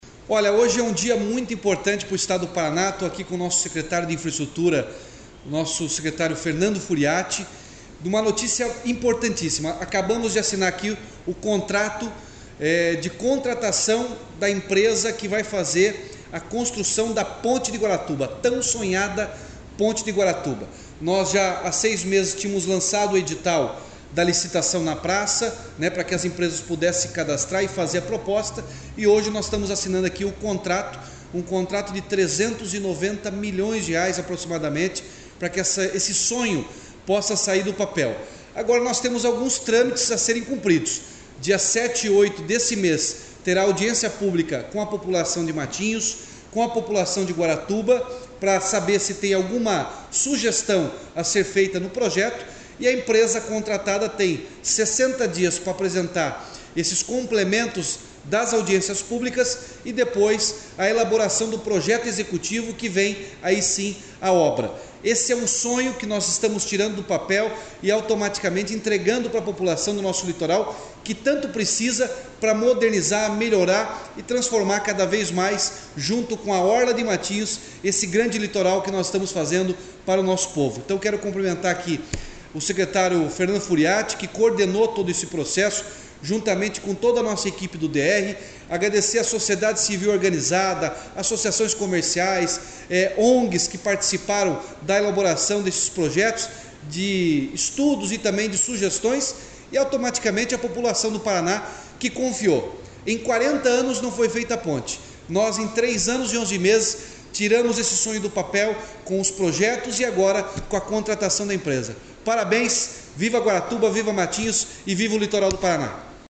Sonora do governador Ratinho Junior, sobre a assinatura do contrato para a construção da Ponte de Guaratuba